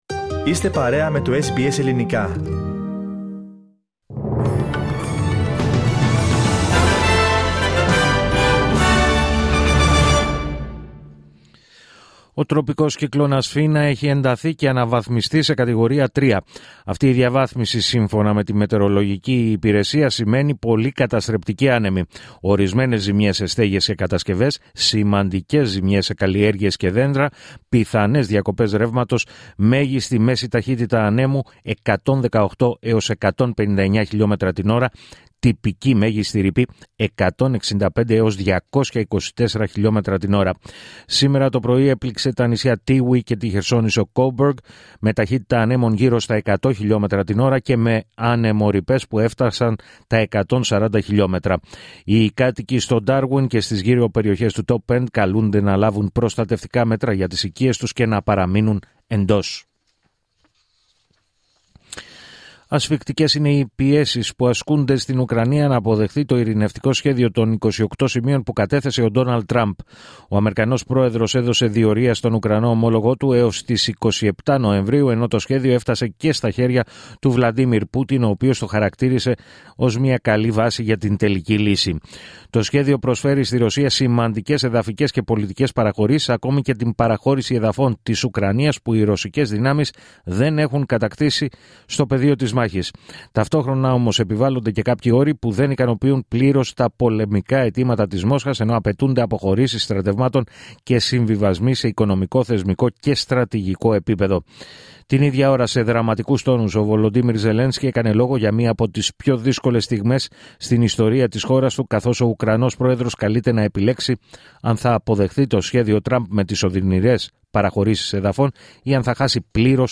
Δελτίο Ειδήσεων Σάββατο 22 Νοεμβρίου 2025